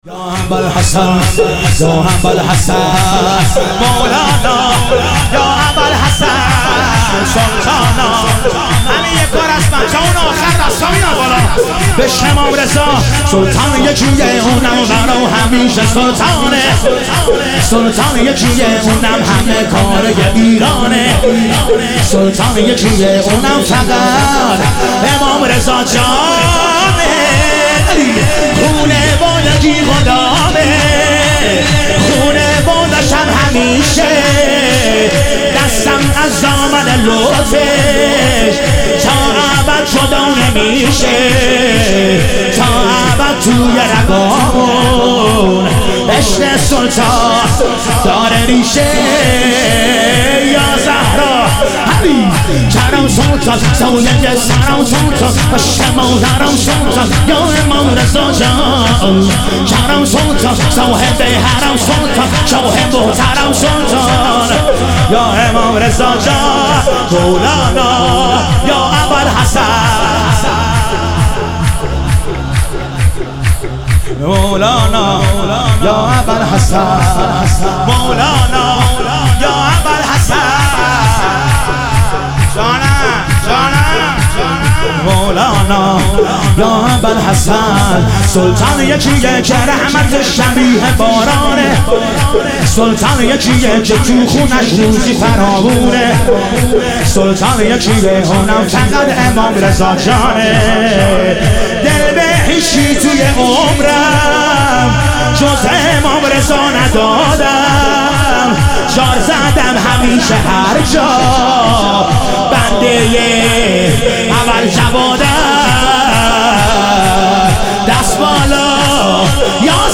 ظهور وجود مقدس امام رضا علیه السلام - شور